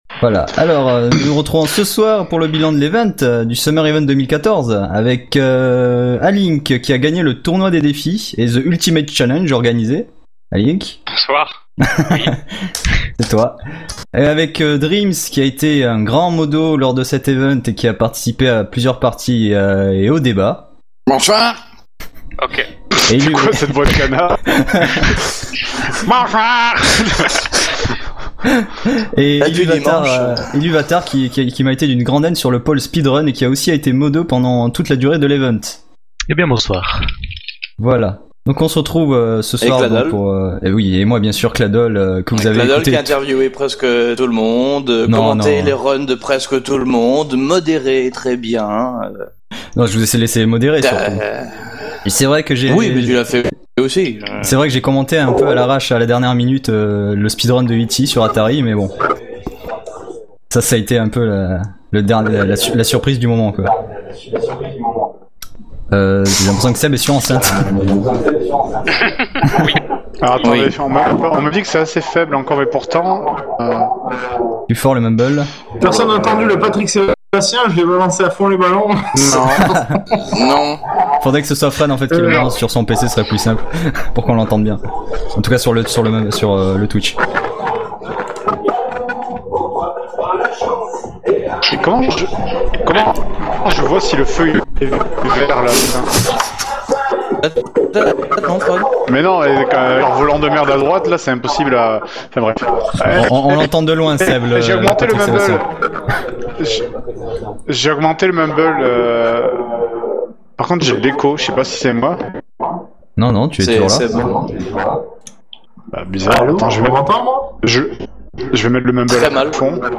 JdG SE - Discussion de cloture de l'event.mp3